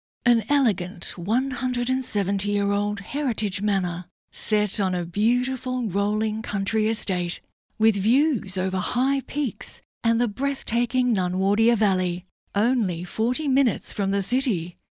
• Warm Friendly
• Rode Procaster mic